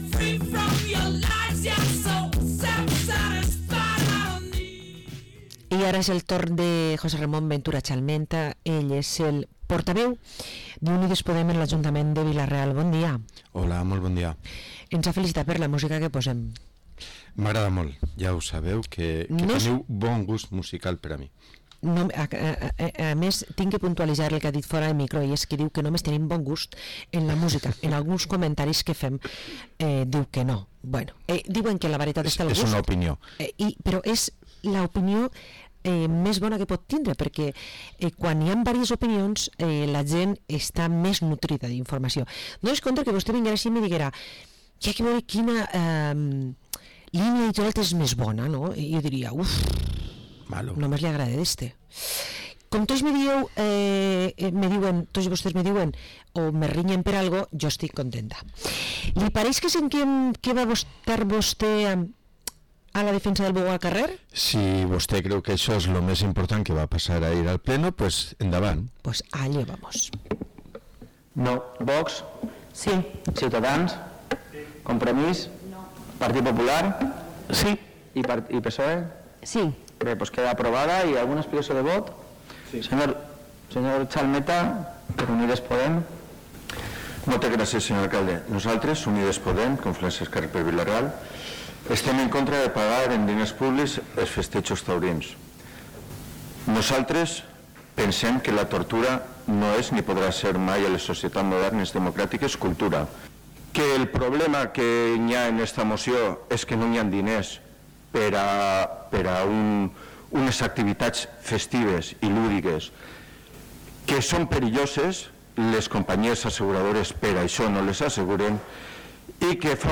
🟣José Ramón Ventura Chalmeta, portaveu d’Unides Podem, ens explica com va anar el ple ahir